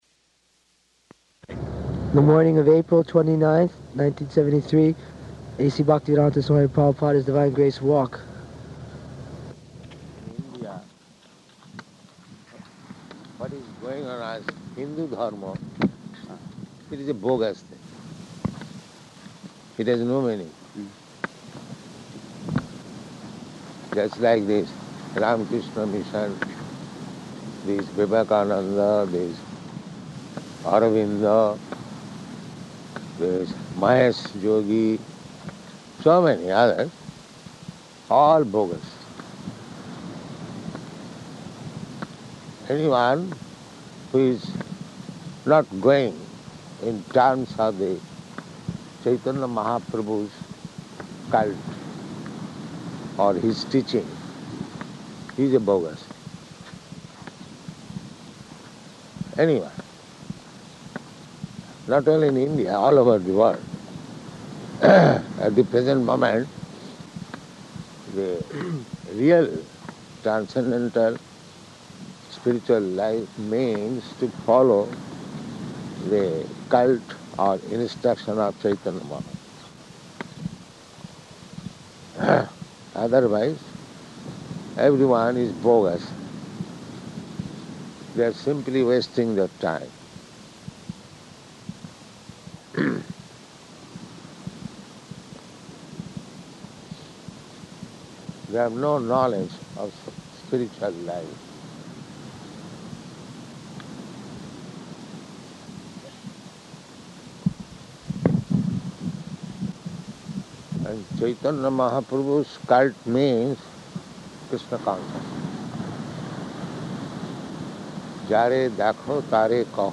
Type: Walk
Location: Los Angeles
[voices yelling in background] What is that?